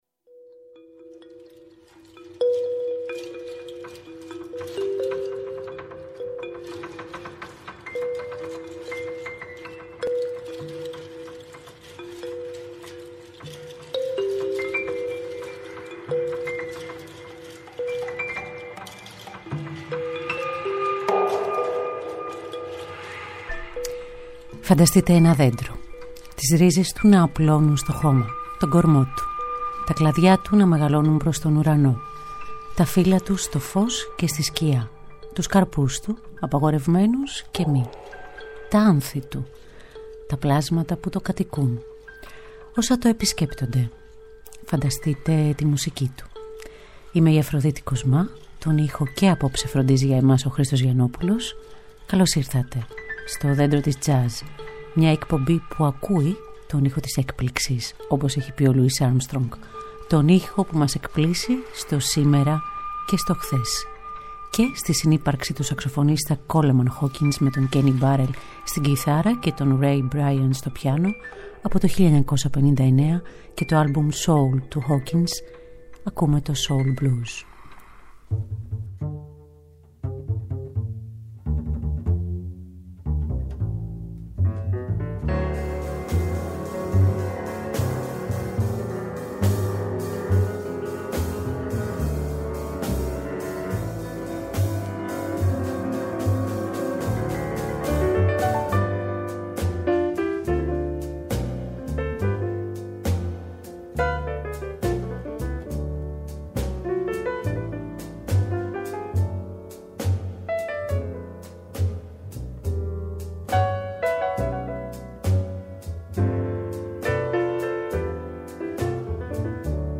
Μουσική Τζαζ
Nuevo Tango
Τενορο Σαξοφωνο